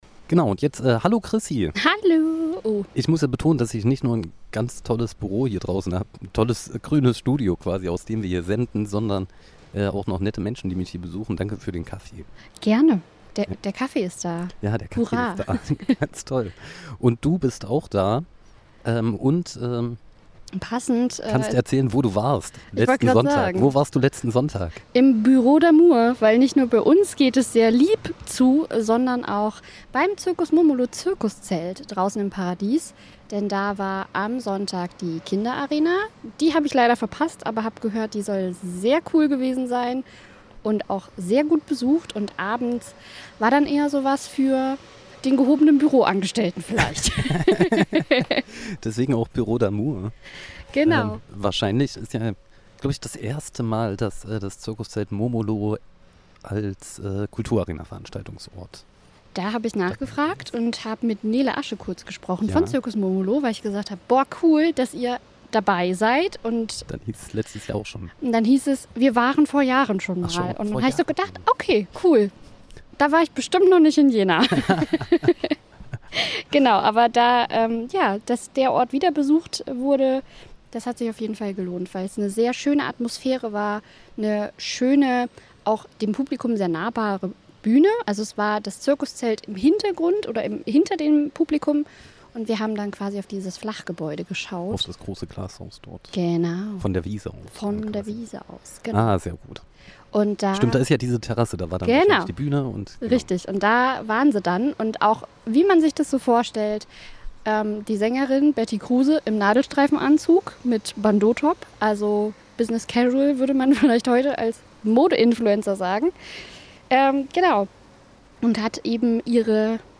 RadioArena Konzertrezension Büro d' Armour